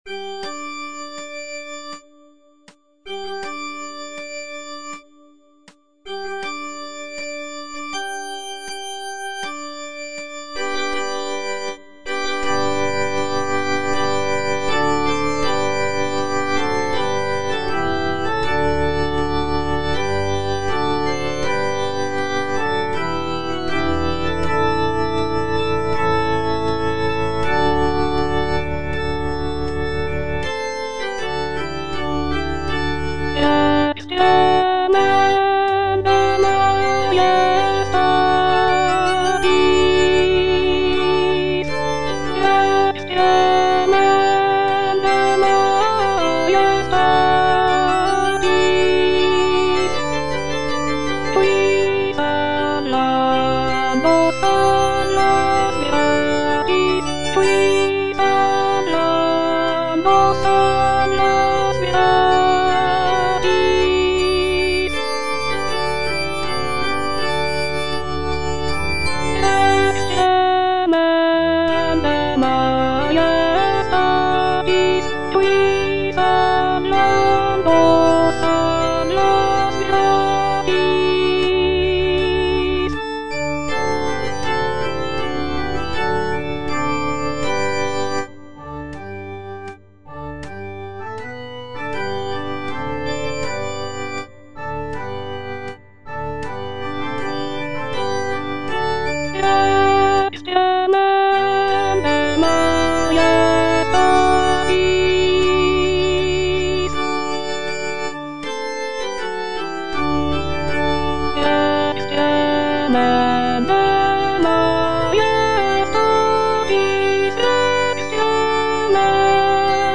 (alto II) (Voice with metronome) Ads stop
is a sacred choral work rooted in his Christian faith.